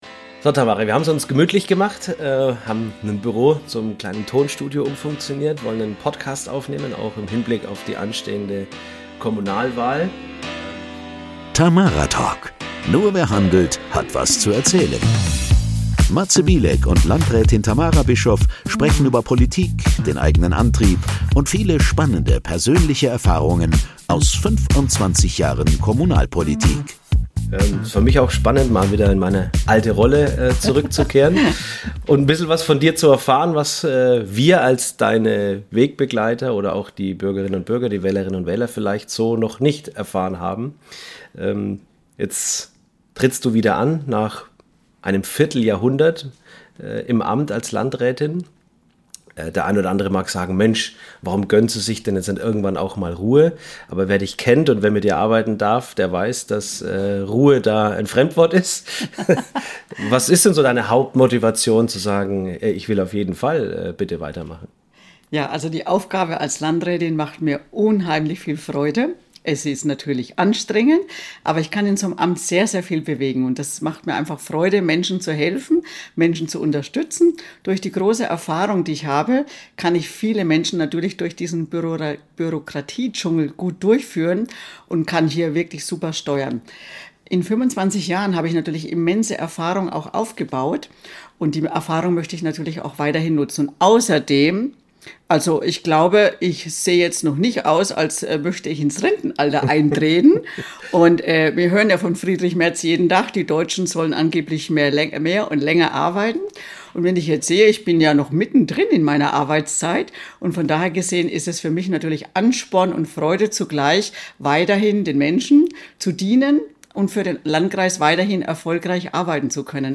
im Gespräch mit Landrätin Tamara Bischof über Politik, Motivation und persönliche Erfahrungen aus 25 Jahren politischem Engagement. In der ersten Episode dreht sich das Gespräch um Tamaras persönliche Motivation und die Herzensangelegenheiten Gesundheit, Bildung und Sicherheit.